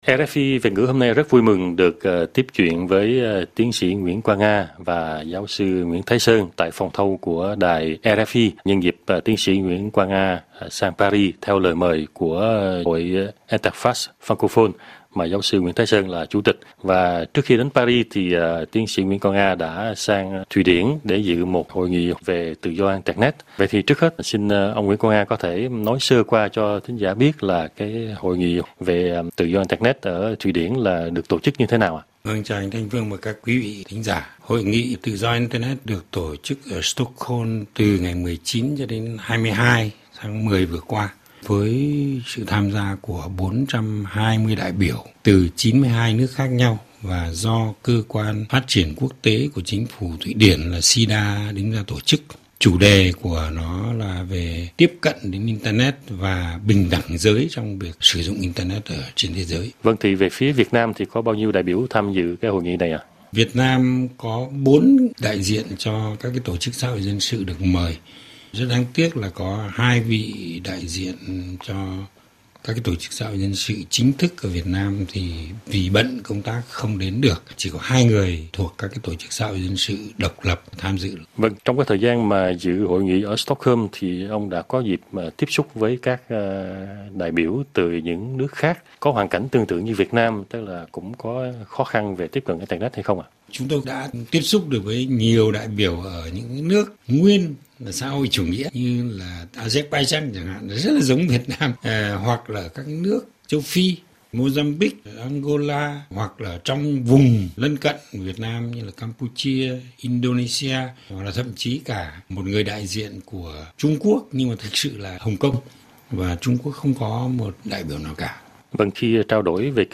trả lời phỏng vấn tại studio RFI